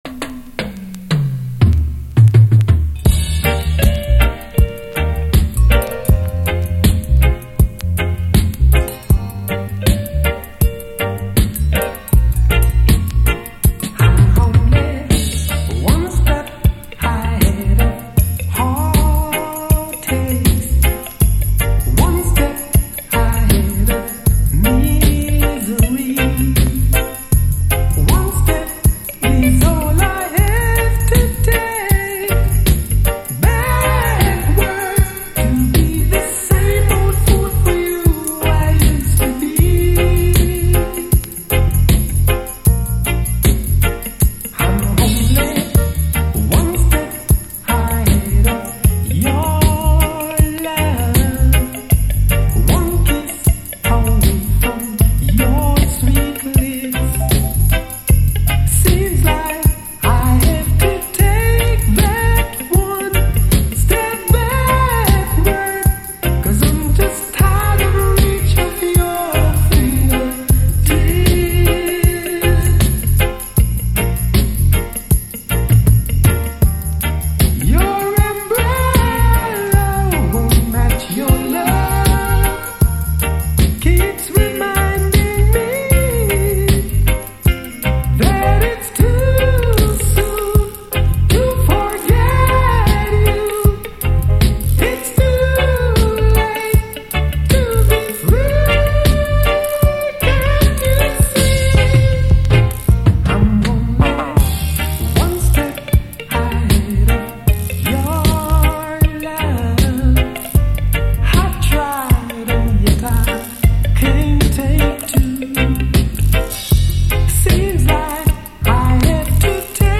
REGGAE
のレゲエ〜ジャマイカン・ソウル・カヴァー！